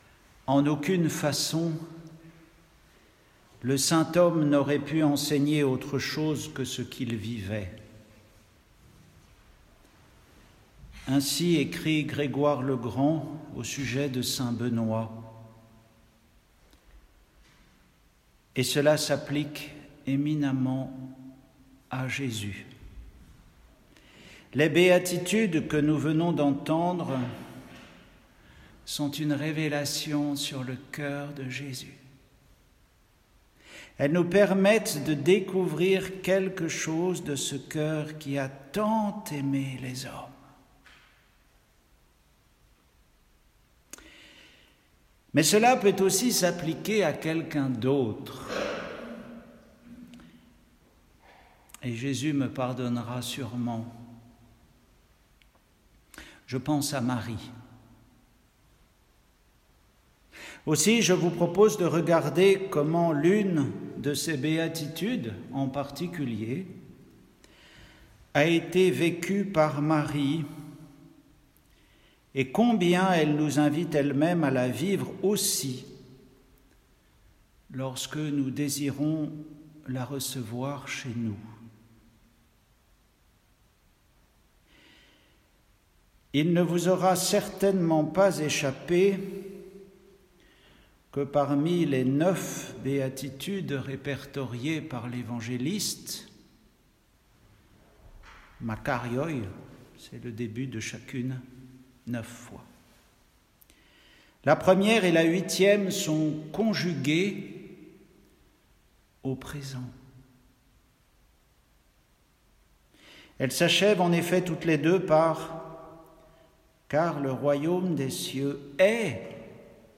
Retrouvez les méditations d’un moine sur les lectures de la messe du jour.